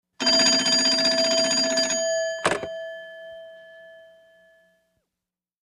Military|Pulse Rotary Rings | Sneak On The Lot
Military field telephone ring and pick up